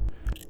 Slurp noise.wav